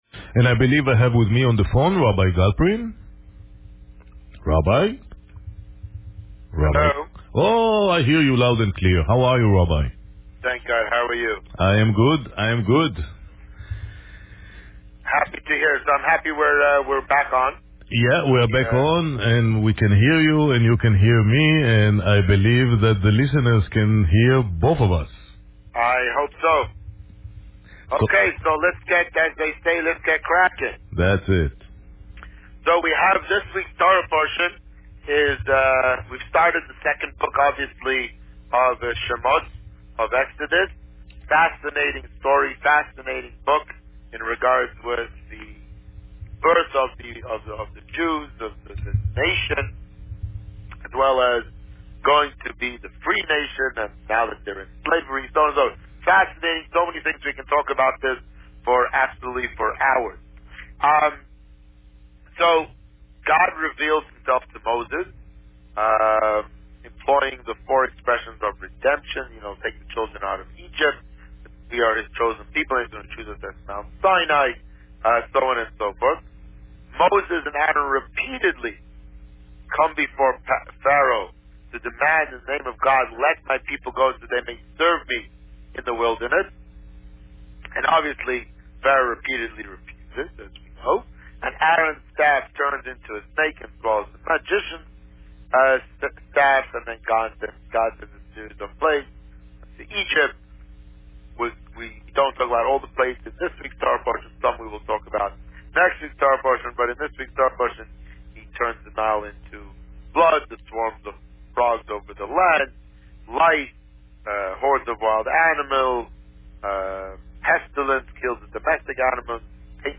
This week, the Rabbi spoke about Parsha Va'eira.